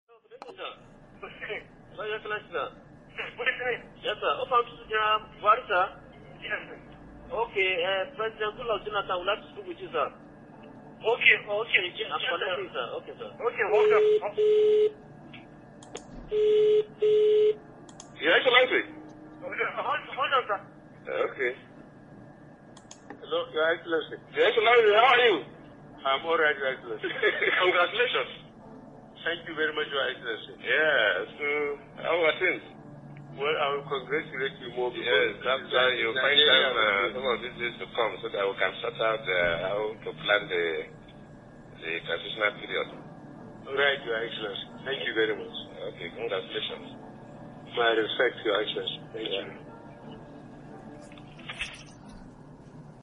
A credible source close to Nigeria’s President Goodluck Jonathan shared with VOA’s Hausa Service audio from a phone call Jonathan made to president-elect Muhammadu Buhari that revealed how he conceded defeat after Saturday and Sunday's presidential election voting.
Nigerian President Goodluck Jonathan calls Muhammadu Buhari to congratulate him